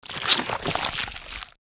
guts_and_gore_68.wav